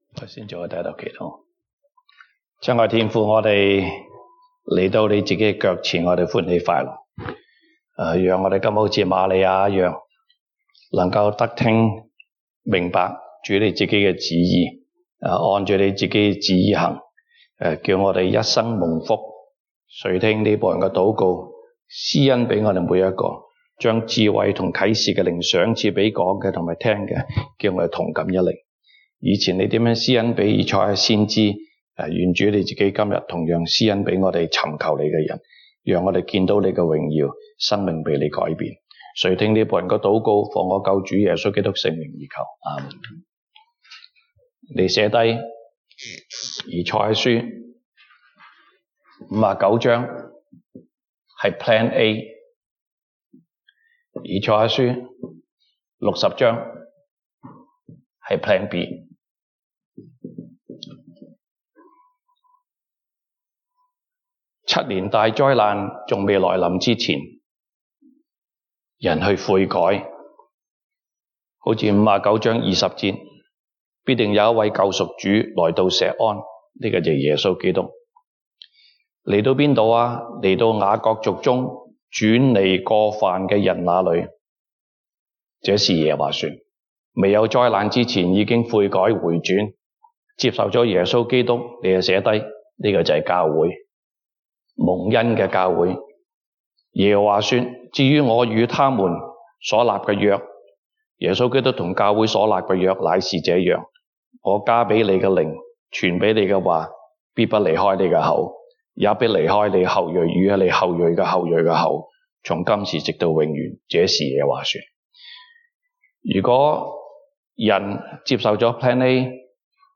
東北堂證道 (粵語) North Side: 主耶穌基督第二次再來